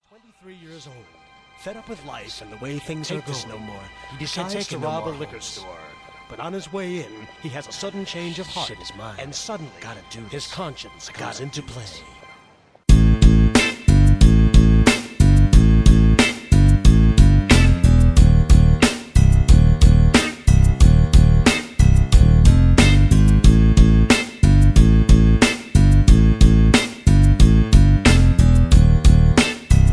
backing tracks
hip hop, rap